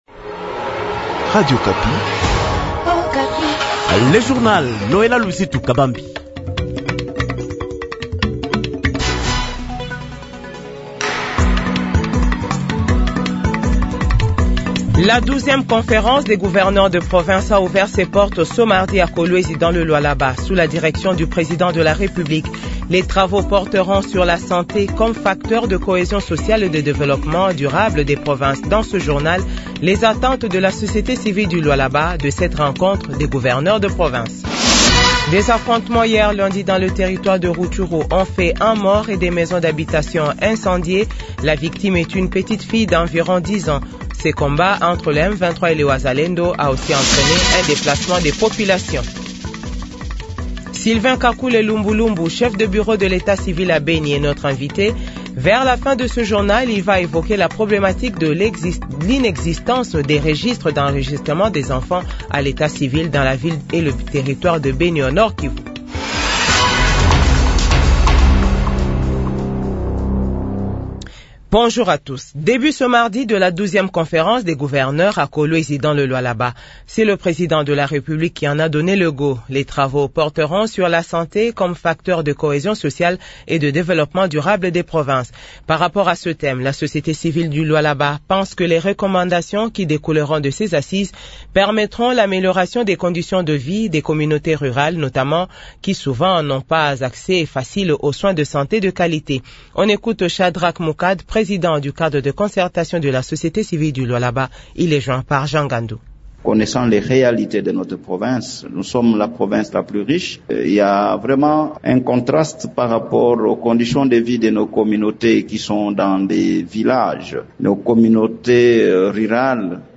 Journal 15h